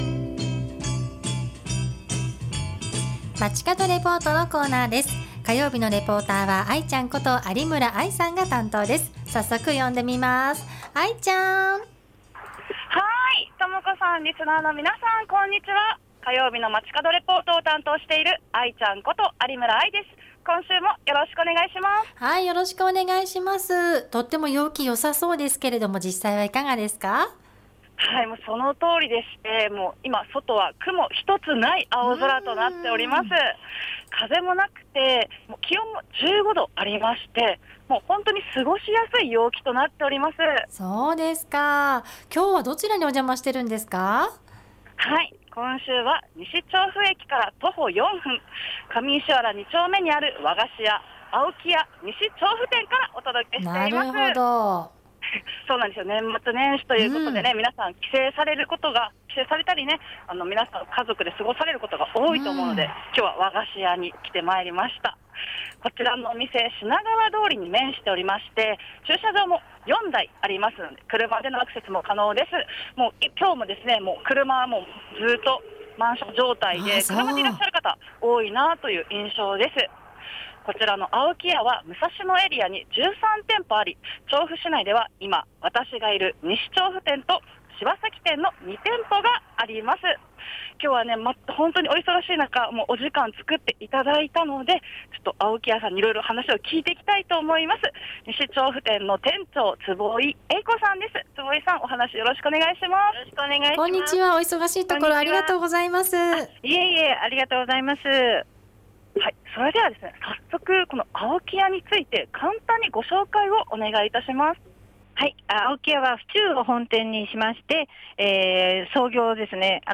今週は西調布にある和菓子屋「青木屋 西調布店」からお届けしました！